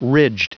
Prononciation du mot ridged en anglais (fichier audio)
Prononciation du mot : ridged